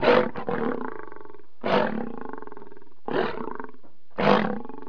جلوه های صوتی
دانلود صدای حیوانات جنگلی 107 از ساعد نیوز با لینک مستقیم و کیفیت بالا